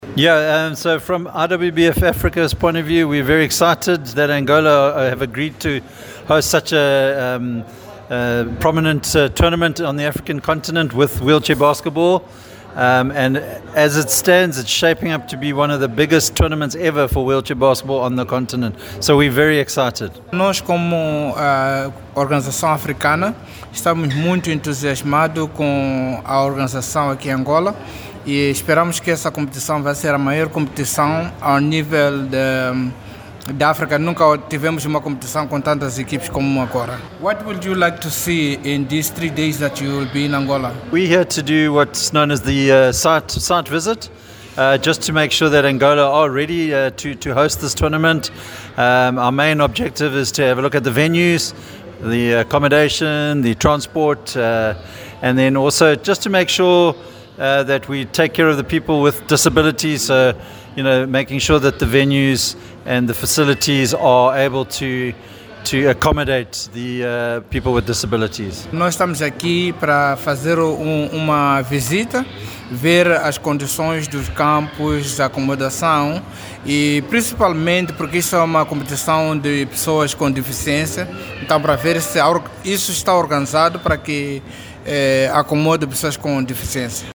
falou à Rádio Cinco na chegada ontem, sábado(27) a Luanda.